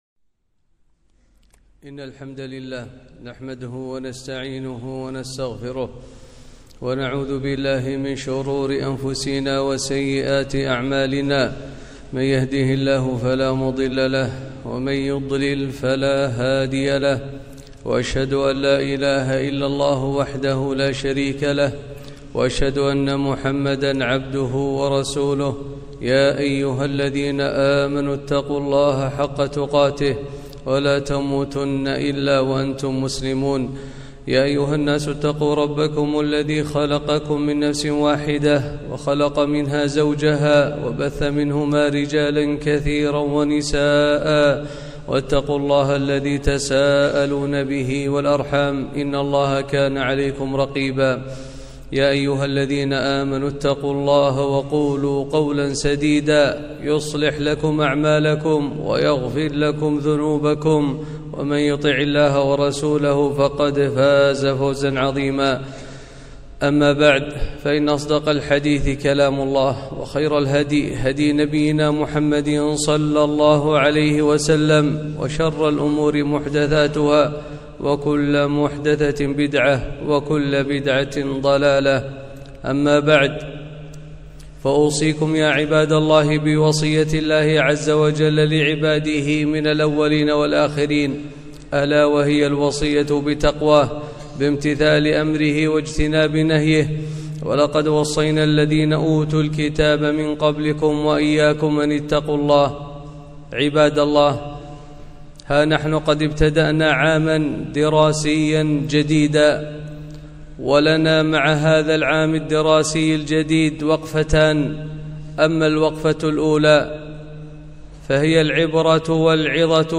خطبة - عام دراسي جديد